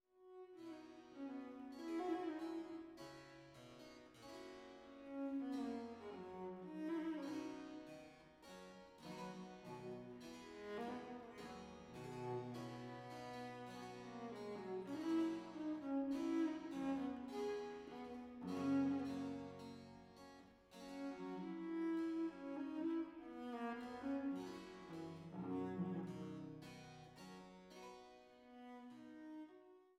Cello
Cembalo